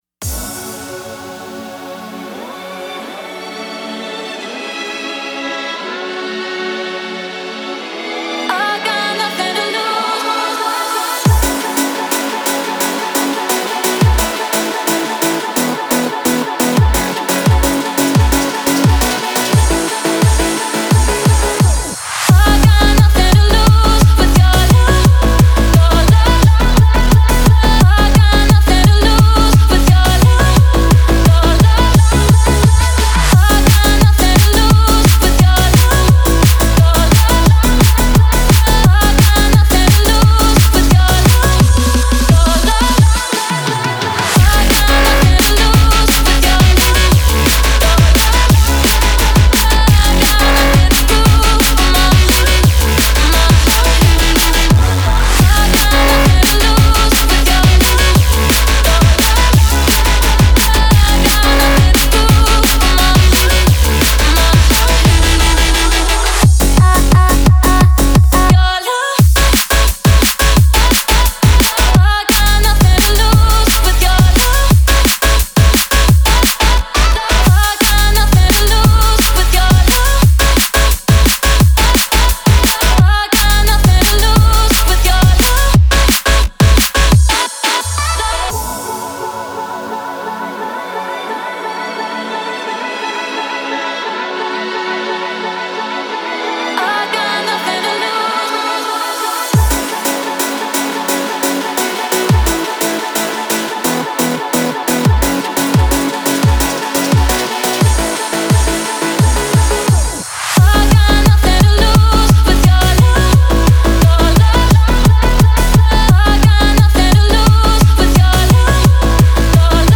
BPM174-174
Audio QualityPerfect (High Quality)
Drum and Bass song for StepMania, ITGmania, Project Outfox
Full Length Song (not arcade length cut)